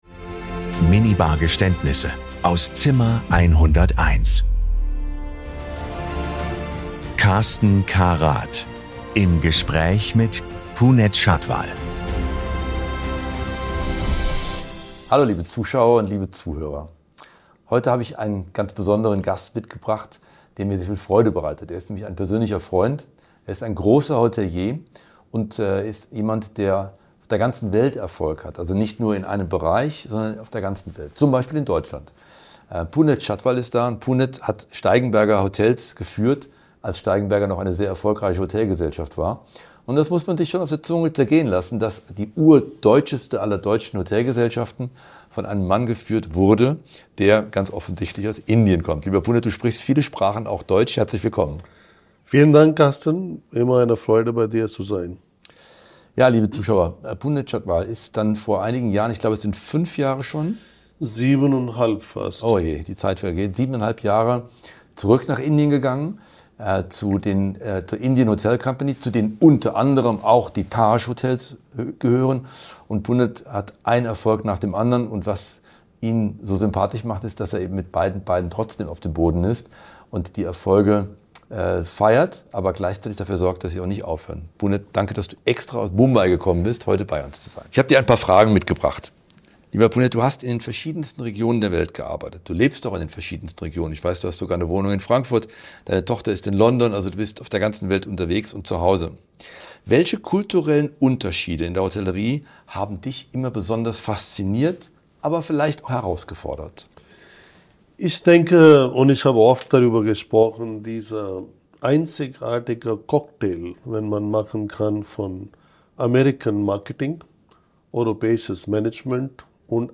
Es wird gelacht, reflektiert und manchmal auch gestanden. Hier wird Hospitality persönlich.